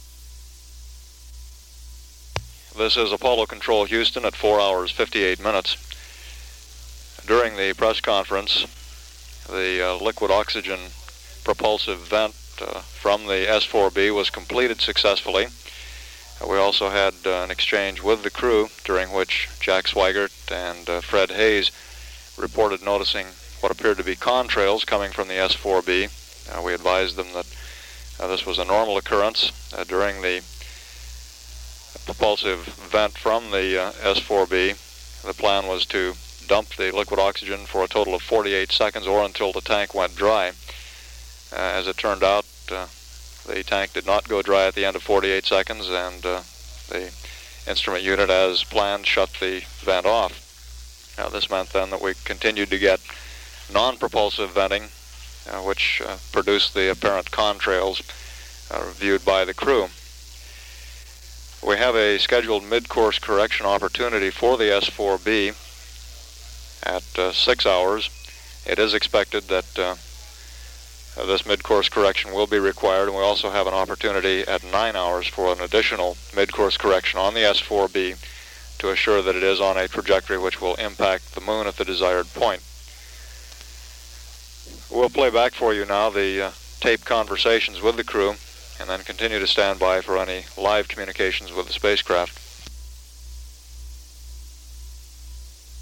PAO loop.